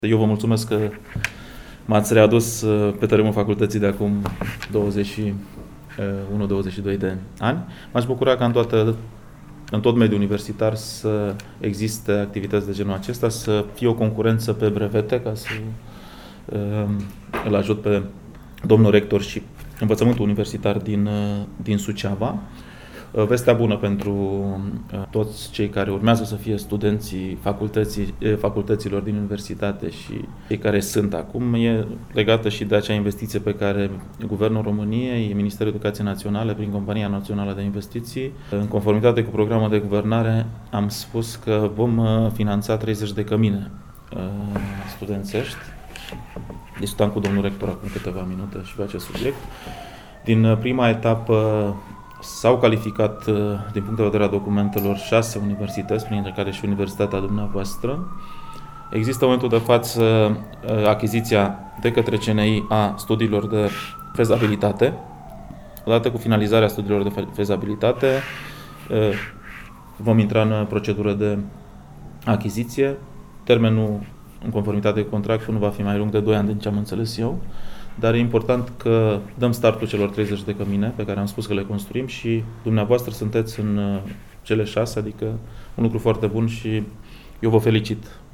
În cadrul conferinței de presă, Liviu Pop a spus că Ministerul Educației Naționale prin intermediul Companiei Naționale de Investiții va finanța 30 de cămine studențești printre care și cele ale Universității “Ştefan cel Mare” din Suceava.
Interviu-liviu-pop-camine.mp3